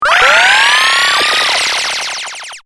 power_button.mp3